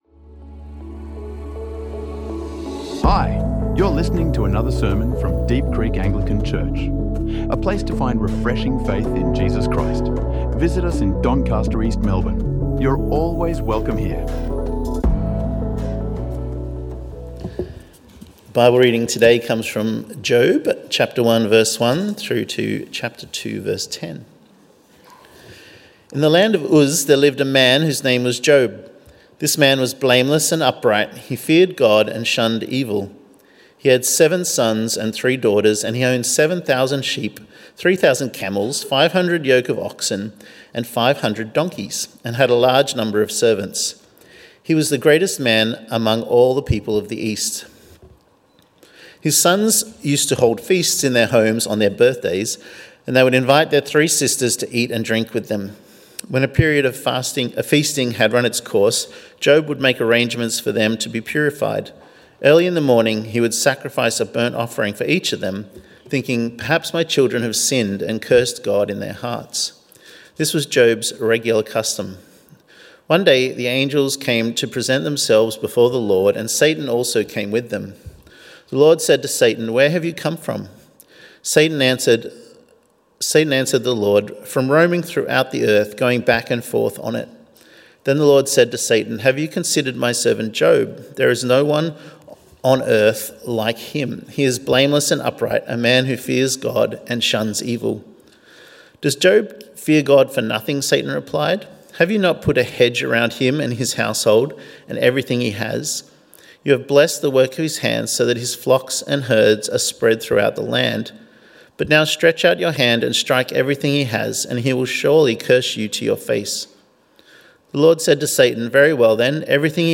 | Sermons | Deep Creek Anglican Church